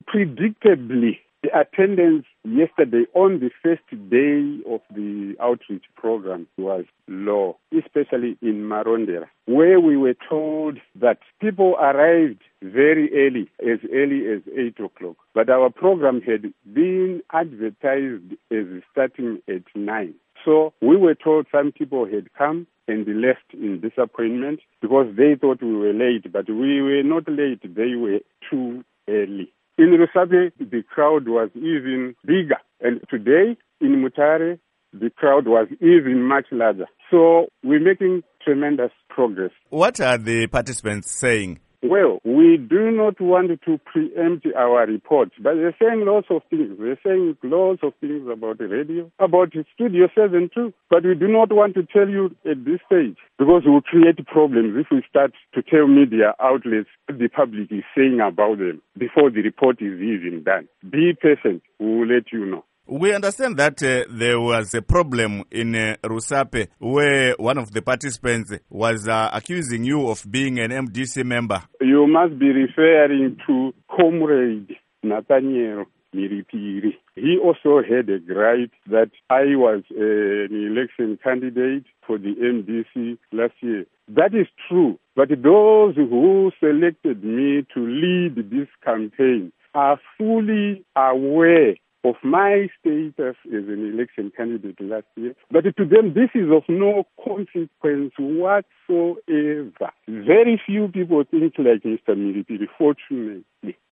Interview With Geoff Nyarota